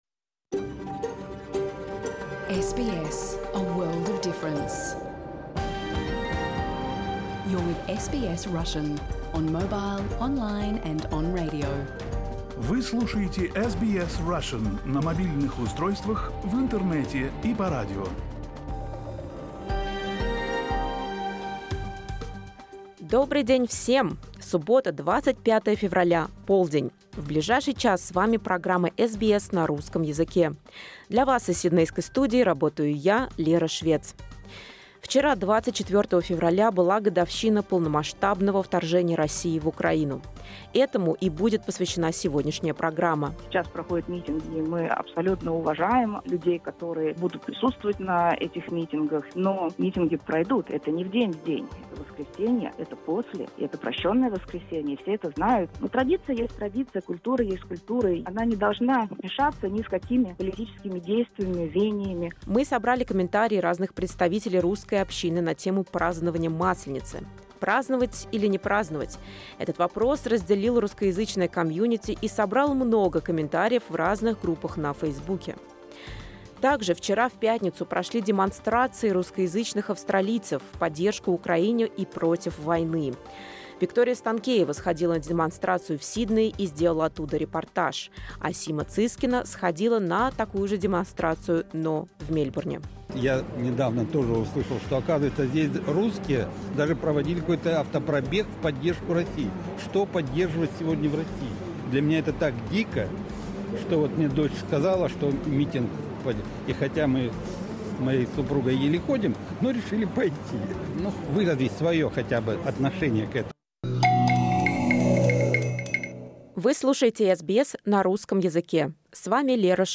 Если вы пропустили эфир, не расстраивайтесь: теперь его можно послушать целиком без внутренних рекламных блоков в наших подкастах. Выпуск новостей в этом подкасте не представлен, так как он публикуется отдельным подкастом.